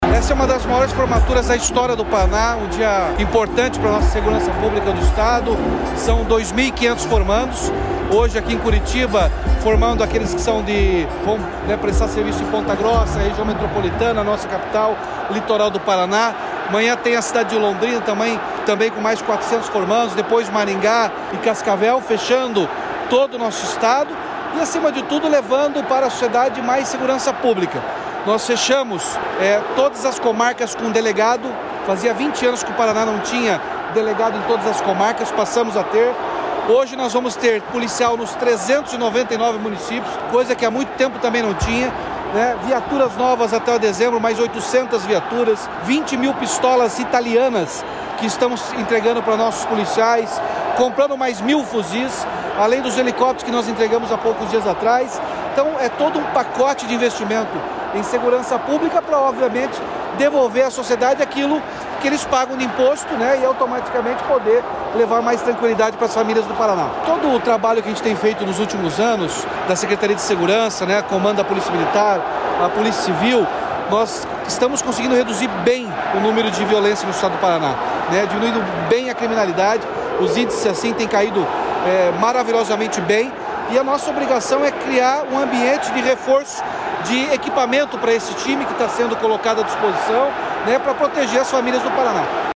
Sonora do governador Ratinho Junior sobre a formatura de 1.452 policiais militares para a macrorregião de Curitiba | Governo do Estado do Paraná
RATINHO JUNIOR - FORMATURA PRACAS CURITIBA.mp3